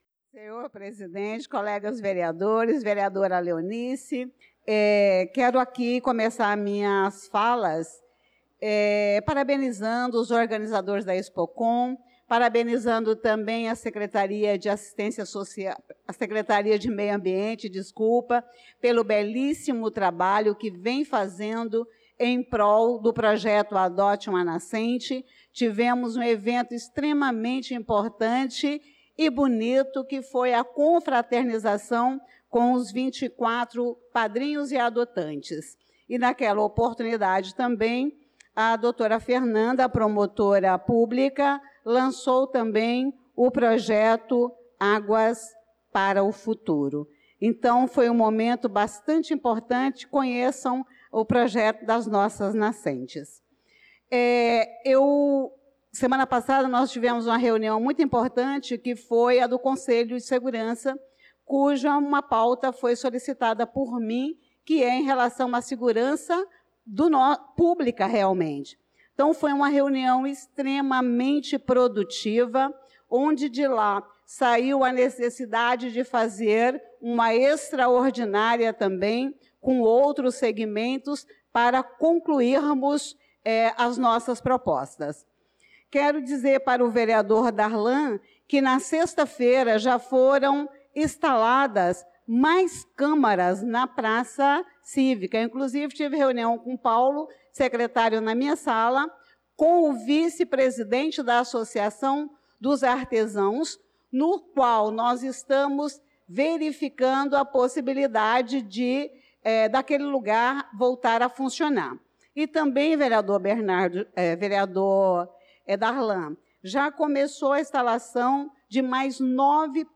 Pronunciamento da vereadora Elisa Gomes na Sessão Ordinária do dia 09/06/2025